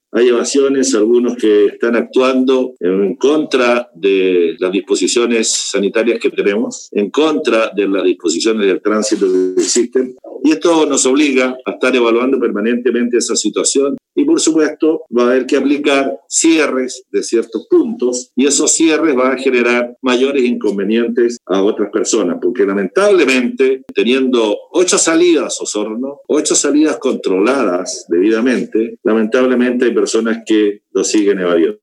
Consultado respecto a la evasión, que se suma a otras dadas a conocer en Cañal Bajo y El Trébol de la ruta 5, el Intendente Harry Jürgensen indicó que analizan aplicar el cierre de ciertos puntos.